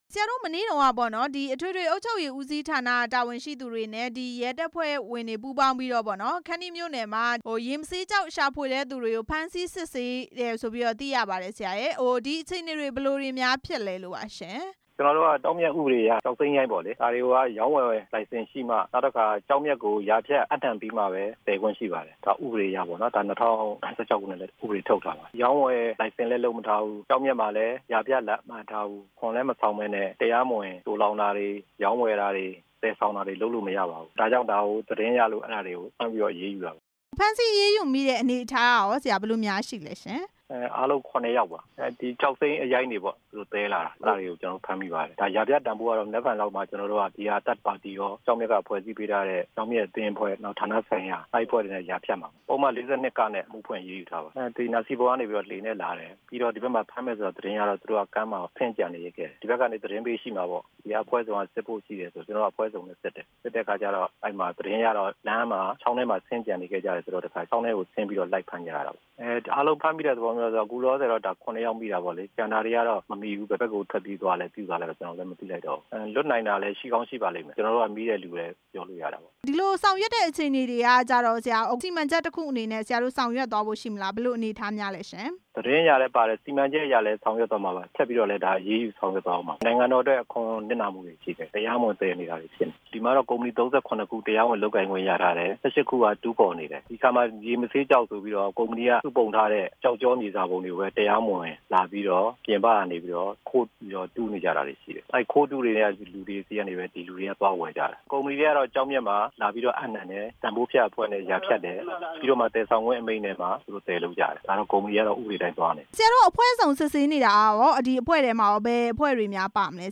တရားမဝင် ကျောက်မျက်သယ်ယူရောင်းမှု စစ်ဆေးနေတဲ့အကြောင်း မေးမြန်းချက်